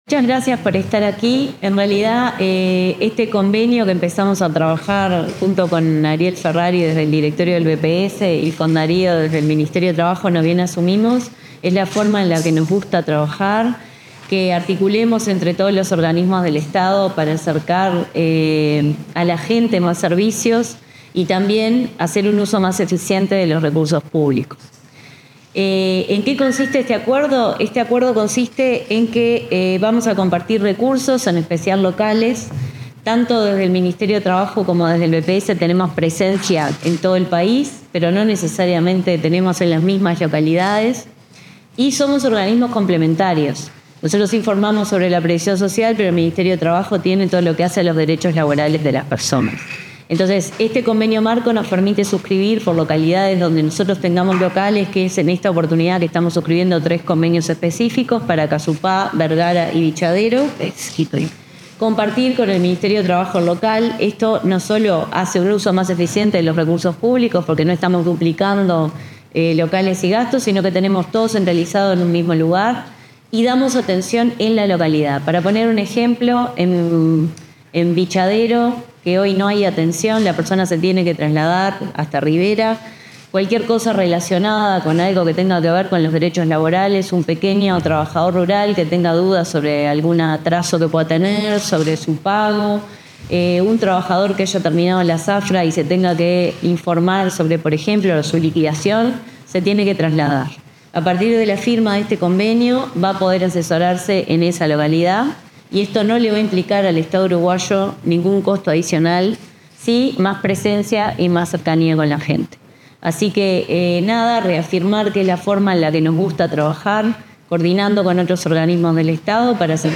Palabras de autoridades en firma de convenio marco entre el BPS y el MTSS
Palabras de autoridades en firma de convenio marco entre el BPS y el MTSS 03/11/2025 Compartir Facebook X Copiar enlace WhatsApp LinkedIn Durante la firma del convenio marco y tres específicos para compartir instalaciones y recursos, la presidenta del Banco de Previsión Social (BPS), Jimena Pardo, y el titular del Ministerio de Trabajo y Seguridad Social (MTSS), Juan Castillo, se expresaron sobre las características del acuerdo.